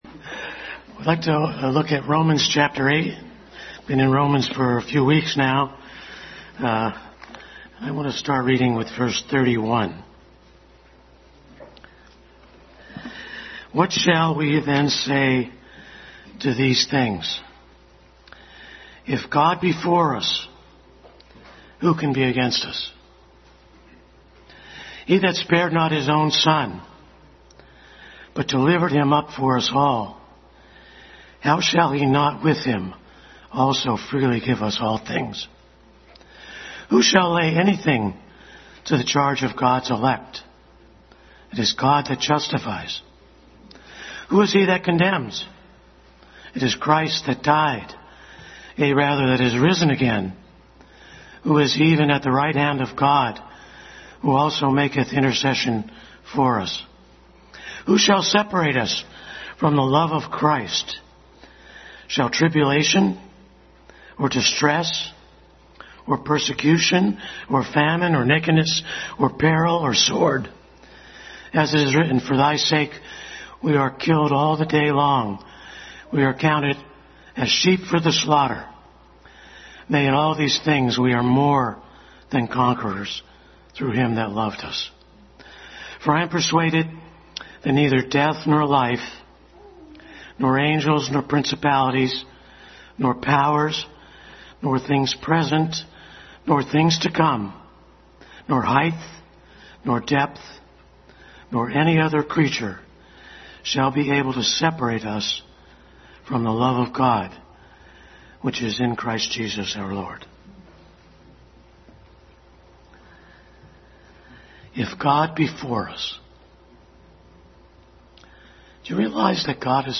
Adult Sunday School Class continued study in Romans.